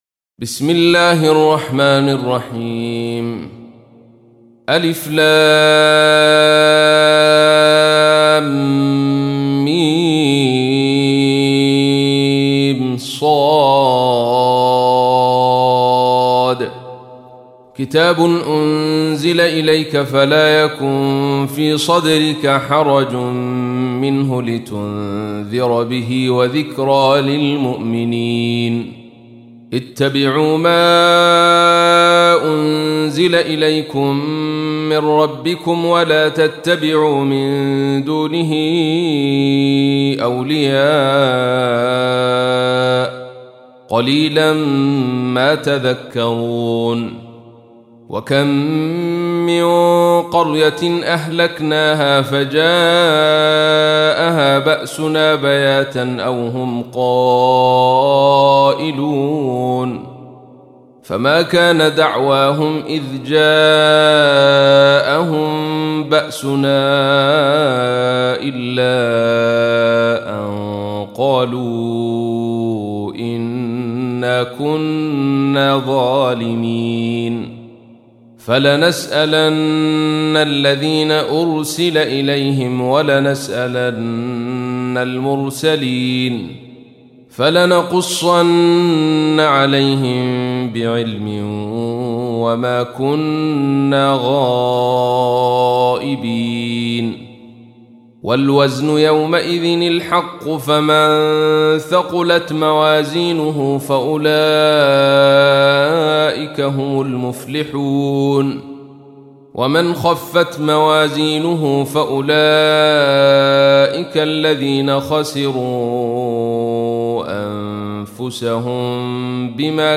تحميل : 7. سورة الأعراف / القارئ عبد الرشيد صوفي / القرآن الكريم / موقع يا حسين